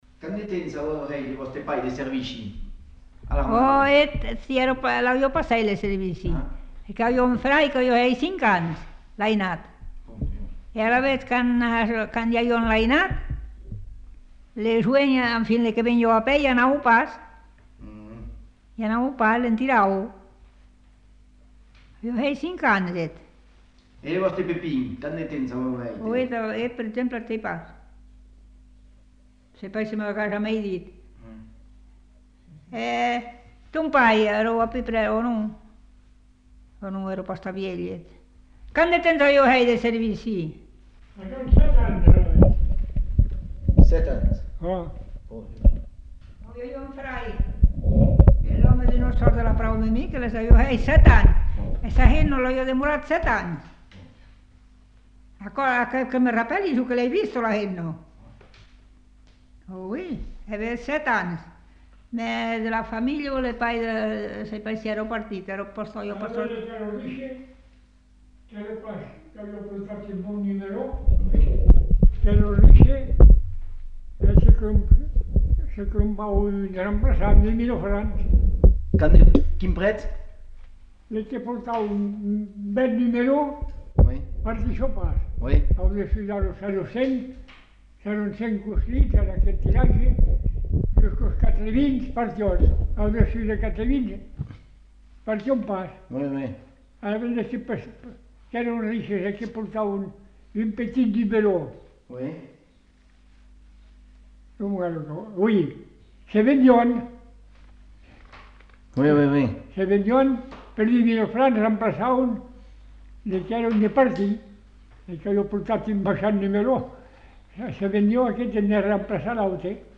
Lieu : Lherm
Genre : témoignage thématique